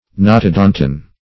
Search Result for " notodontian" : The Collaborative International Dictionary of English v.0.48: Notodontian \No`to*don"tian\, n. [Gr. nw^ton the back + 'odoy`s, 'odo`ntos a tooth.]
notodontian.mp3